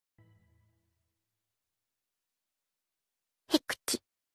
Play, download and share Estornudo kawaiii original sound button!!!!
estornudo-kawaiii.mp3